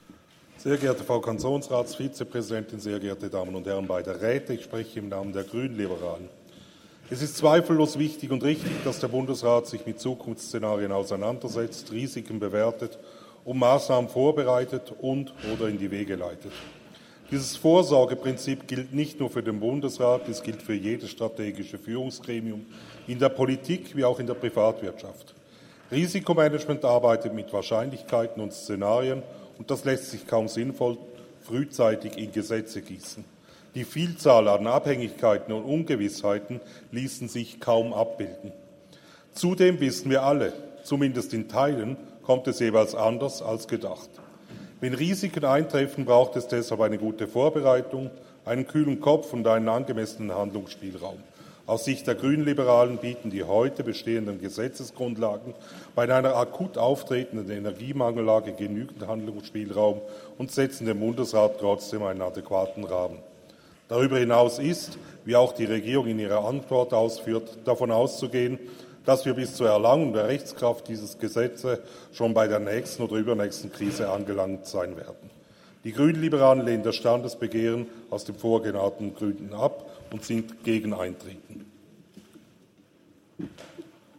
Session des Kantonsrates vom 18. bis 20. September 2023, Herbstsession
18.9.2023Wortmeldung